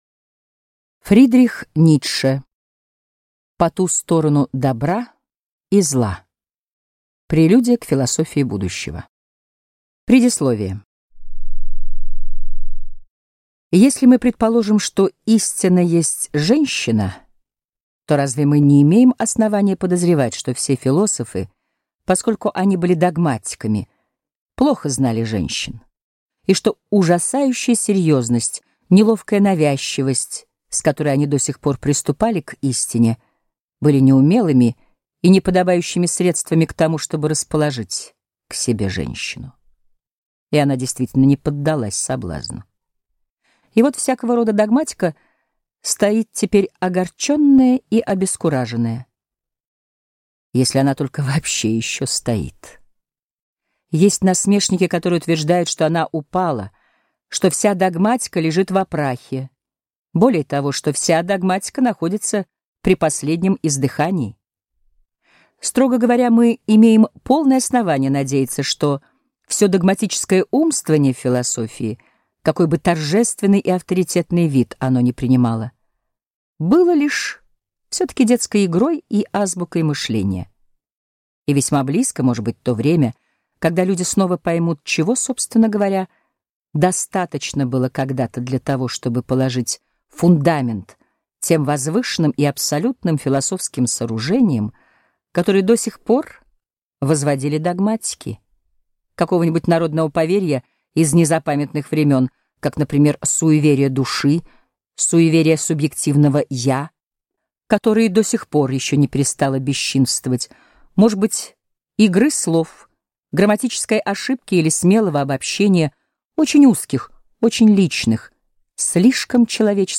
Аудиокнига По ту сторону добра и зла: Прелюдия к философии будущего | Библиотека аудиокниг